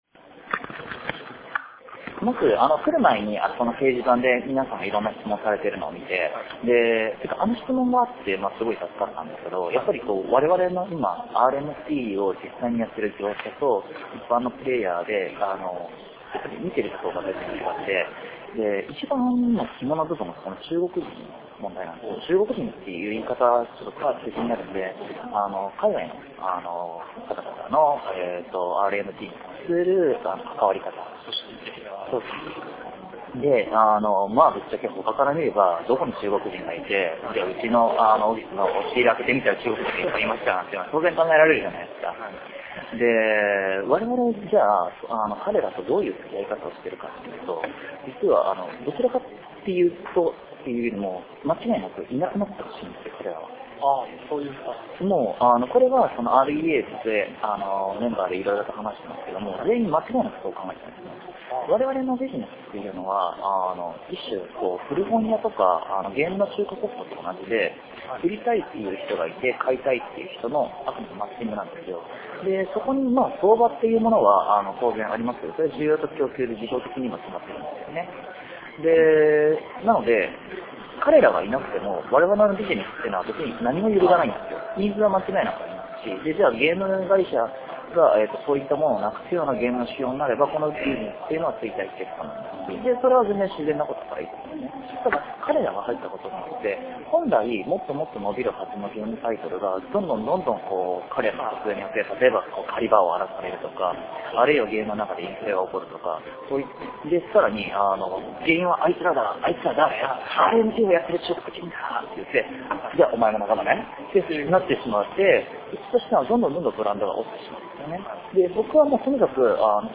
MMOに関わる様々な人との対談、インタビューなどをそのまま保存しています。
生の状況をそのまま感じれるよう、録音時の会話をそのままアップしている時もあります。